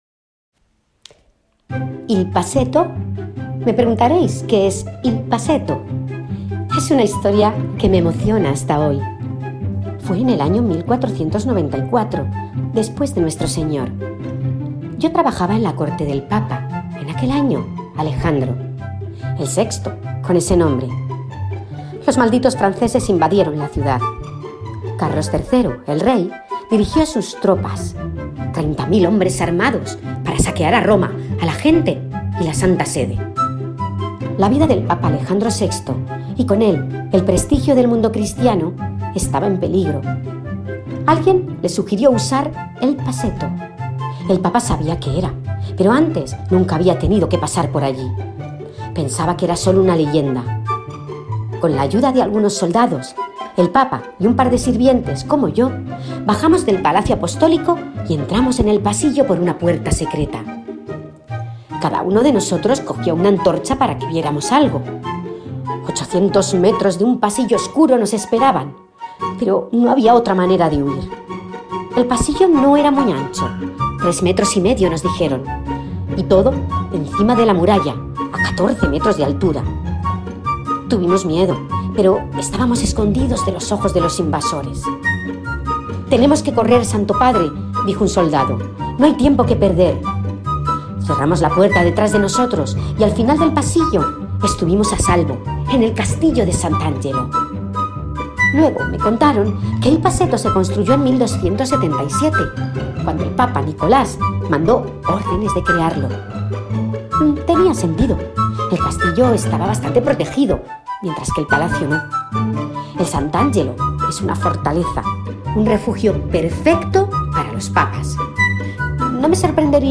Una sirvienta del Papa Alejandro VI cuenta cómo escaparon